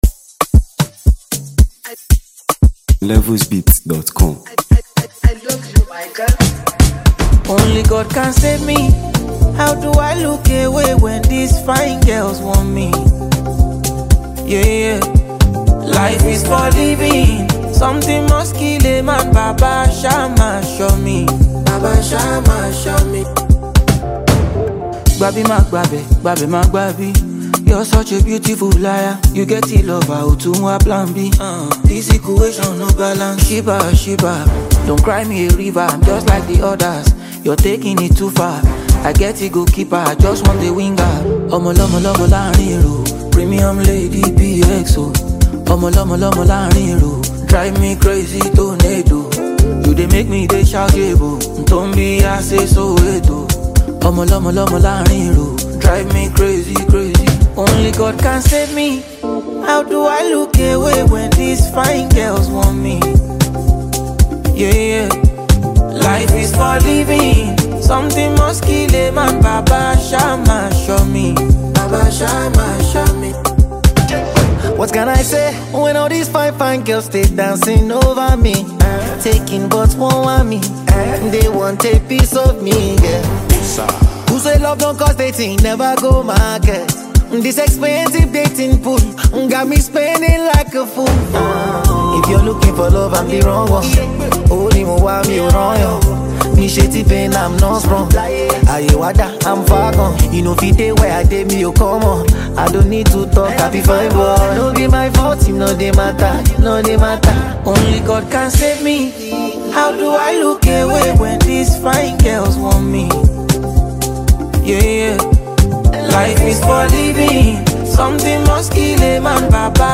Known for redefining Afrobeats with elegance and consistency
stirring single